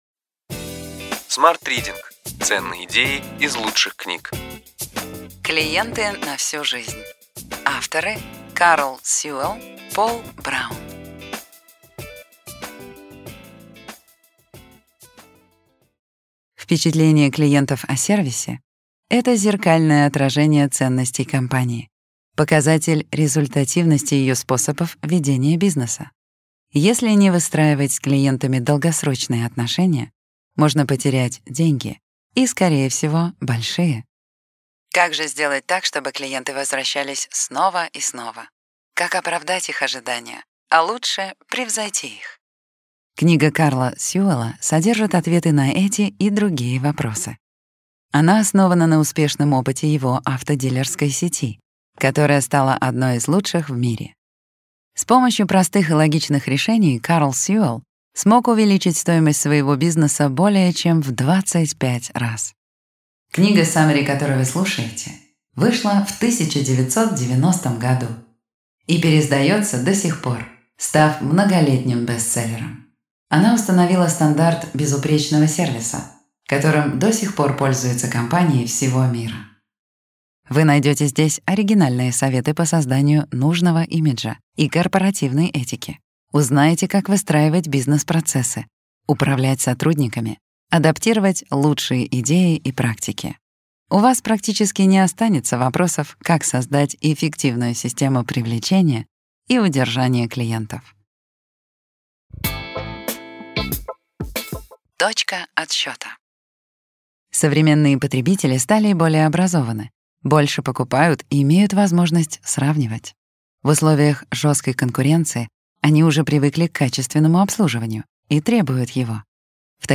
Аудиокнига Ключевые идеи книги: Клиенты на всю жизнь.